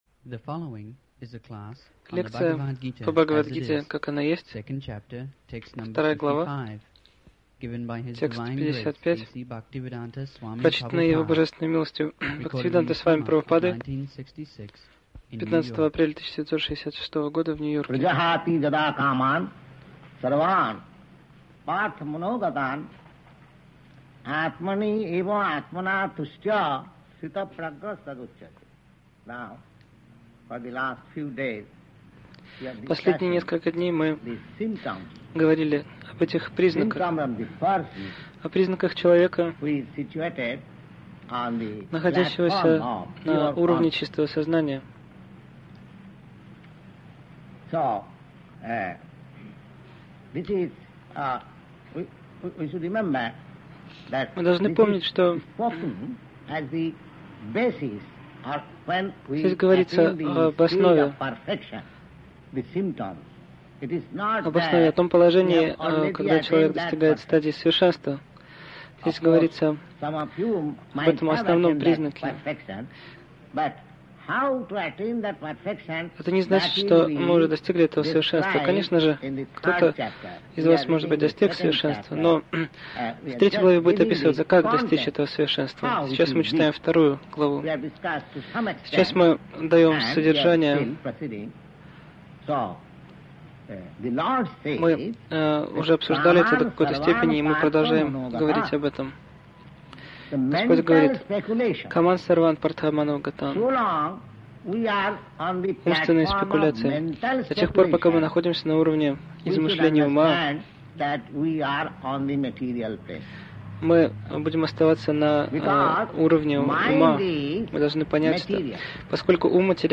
Милость Прабхупады Аудиолекции и книги 21.04.1966 Бхагавад Гита | Нью-Йорк БГ 02.55-56 Загрузка...